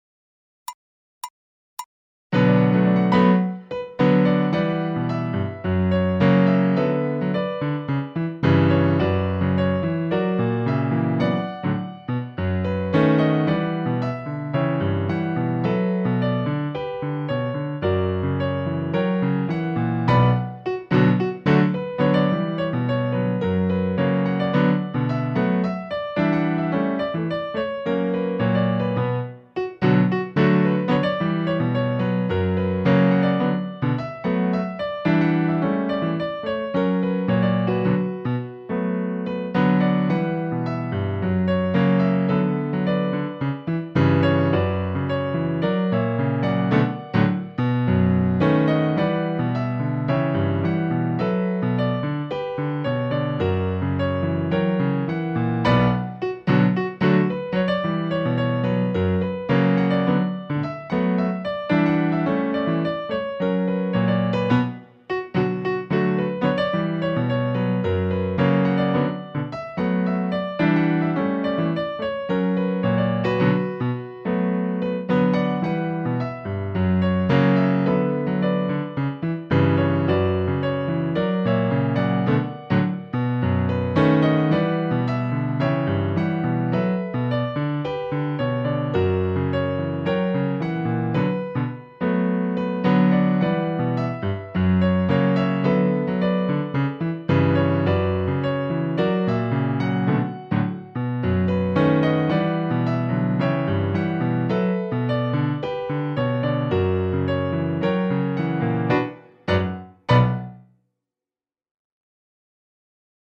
Piano c/ Clique
8-CACADA-AO-CACADOR-MIX-1-COM-CLICK-m2_-piano.mp3